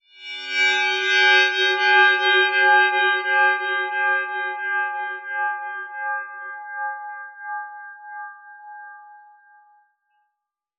metallic_glimmer_drone_01.wav